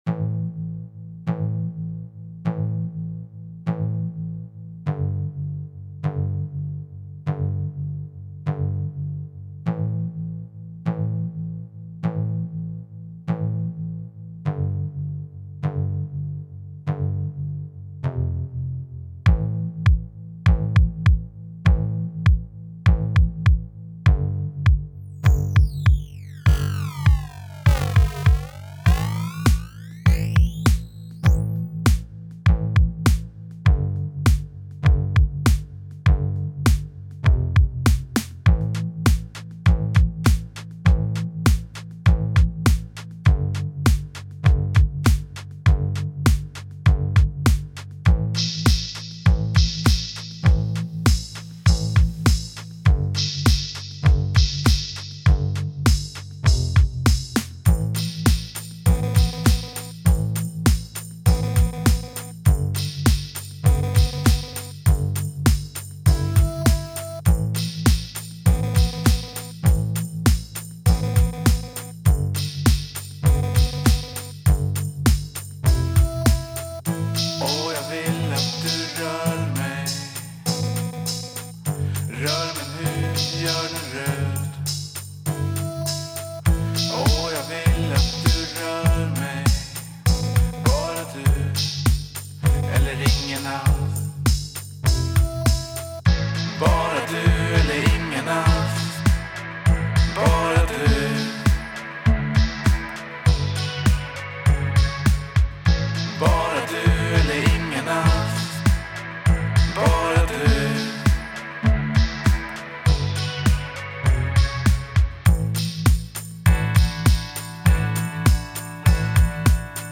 isolated
Bought a DT2 two months ago and have been learning how to use it by remixing some of my old indierock songs.